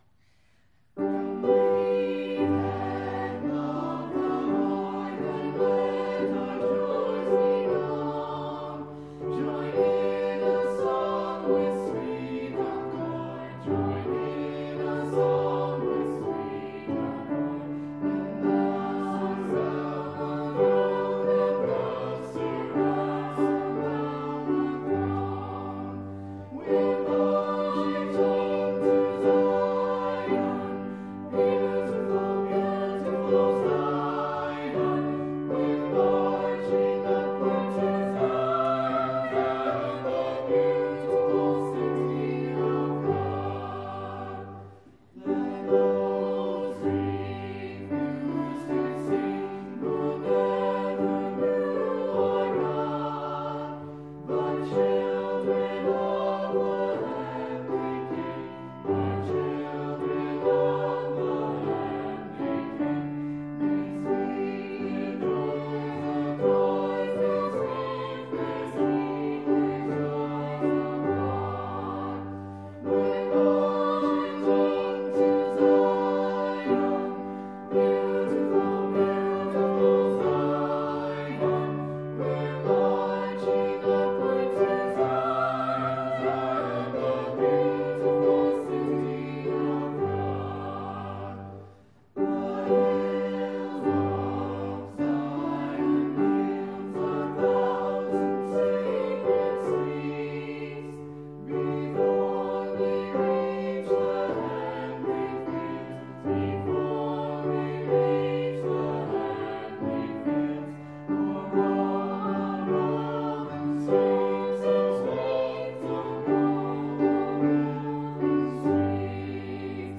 Hymns
4.19.20-Hymns.mp3